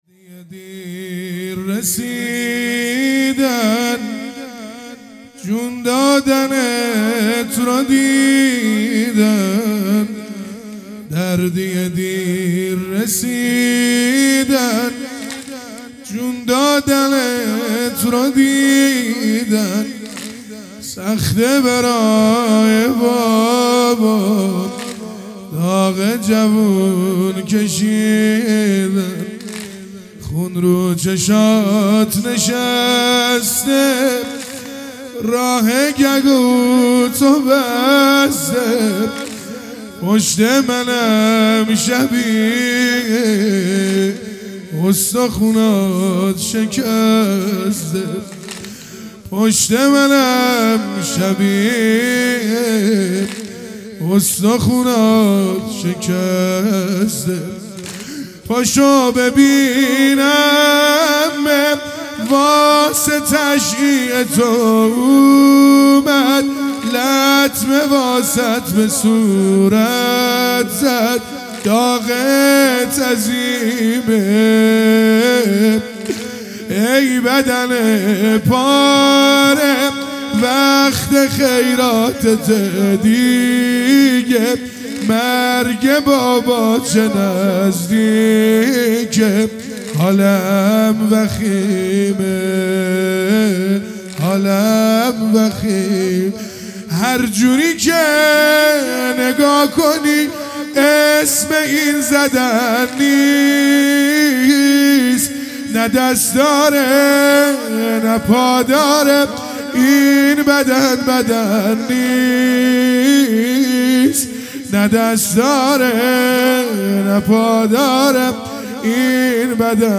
دهه اول محرم الحرام | شب هشتم | واحد
دهه اول محرم الحرام 1444